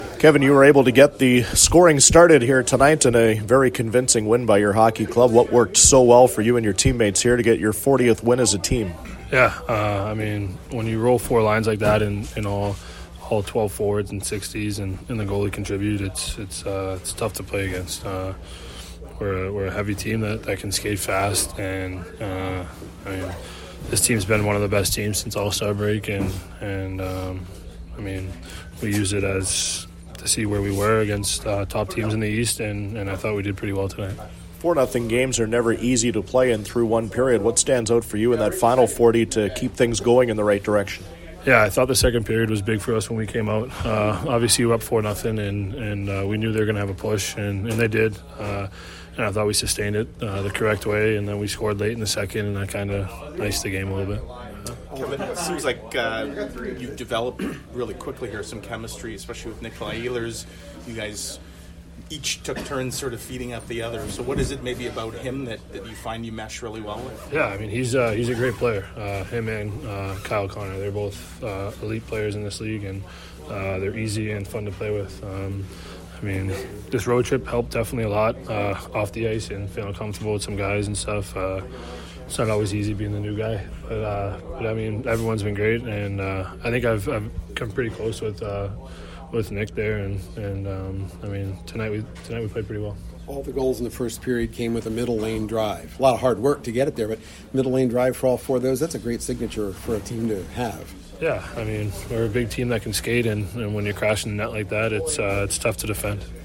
Post-game from the Jets dressing room as well as from Coach Maurice.